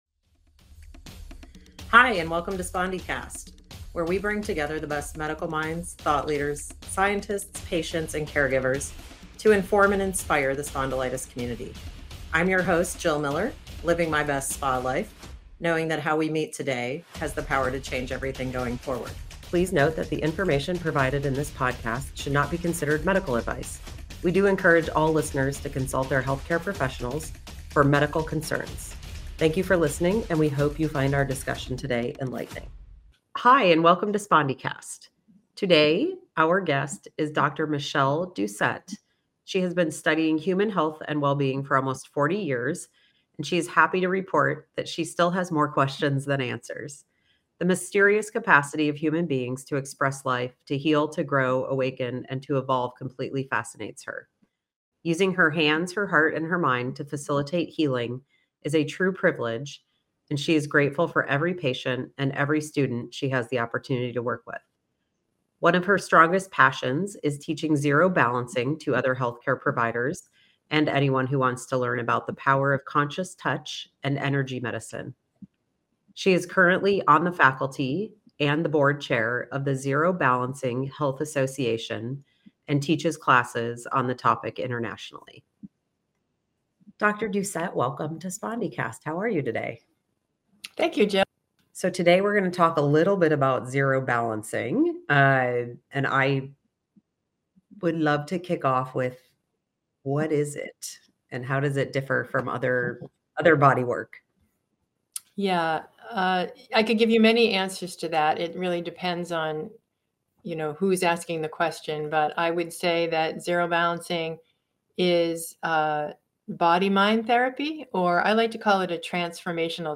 Introducing Spondycast, the newest and most exciting source of information and inspiration for people with spondyloarthritis. Every week, we bring you in-depth conversations with the leading experts in the field, who will share their knowledge and experience on topics related to the diagnosis, treatment, and management of this complex condition.